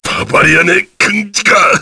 Gau-Vox_Dead_kr.wav